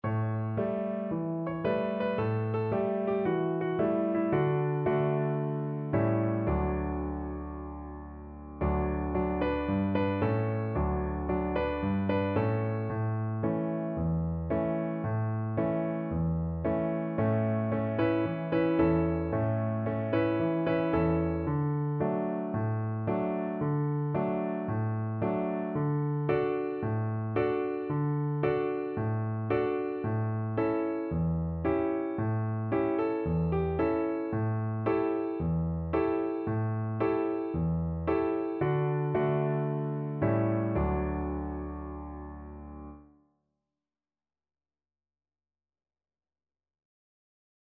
Cheerfully! =c.112
Traditional (View more Traditional Violin Music)